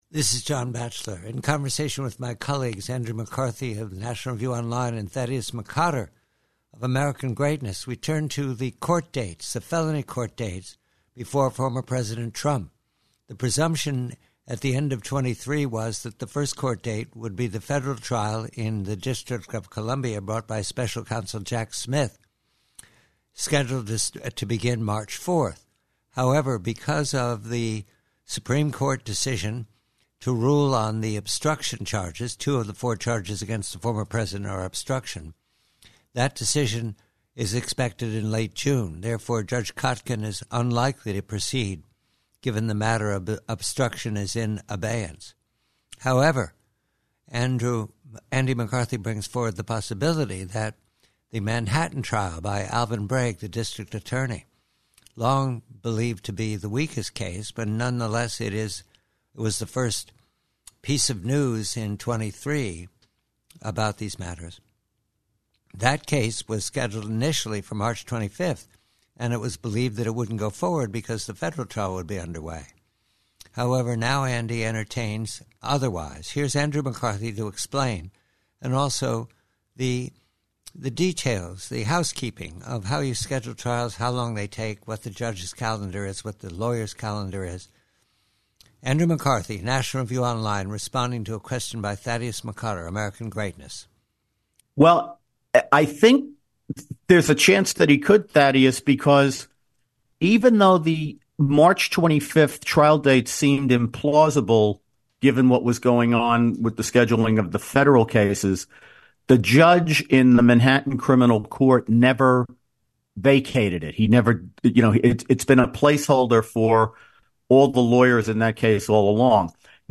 PREVIEW: TRUMP: TRIALS: Part of a conversation re the felony trials facing former President Trump with National Review Andrew McCarthy and American Greatness: the court calendar is cuttered with ifs and unknowns; however there is the strong posibility tha